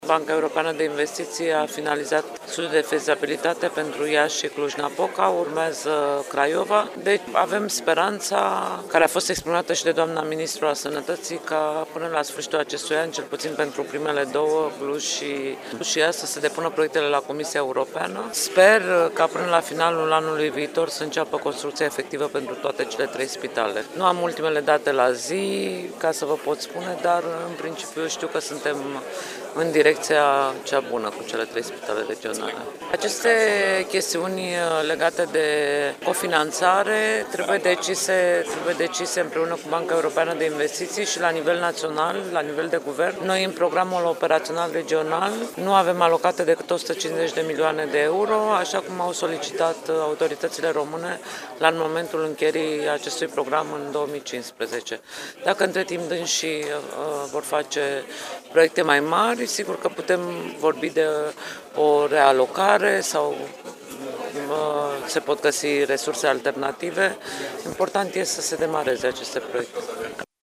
Comisarul Corina Crețu a venit și cu o serie de completări în cazul în care costurile pentru cele trei proiecte ar fi mult mai ridicate:
corespondență Bruxelles